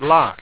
w1_lock.wav